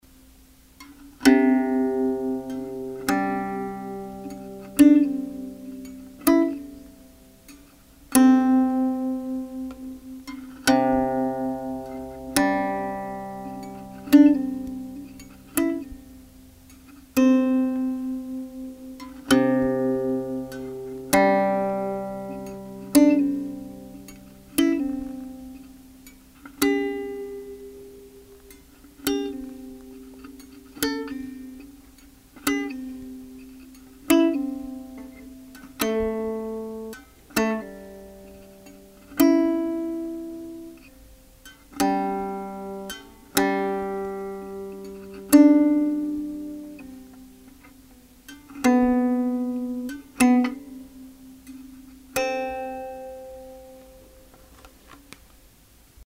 三味線
駒：象牙（オリジナル）